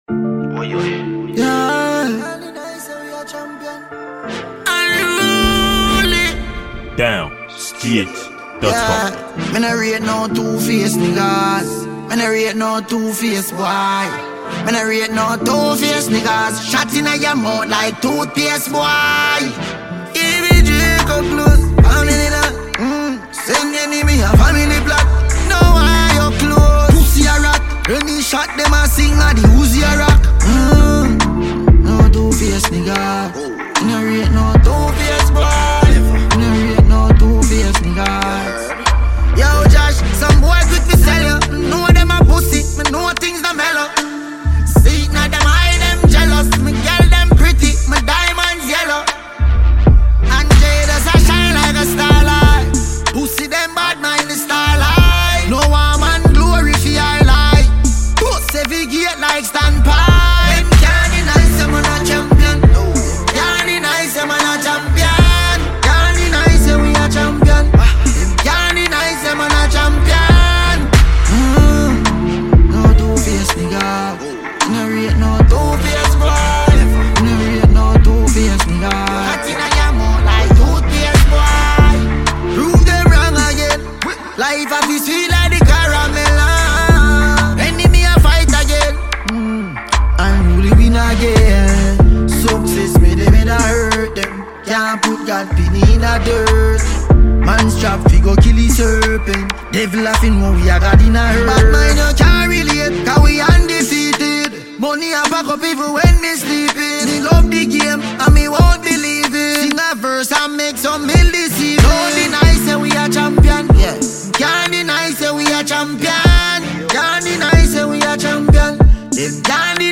It is a free mp3 dancehall music for download.